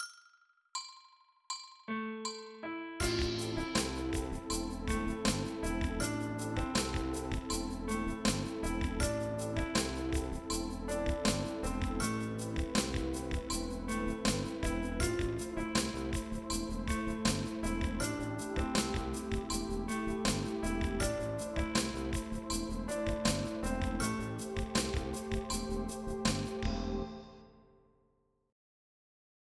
Taką frazę też możemy z powodzeniem "zagęścić":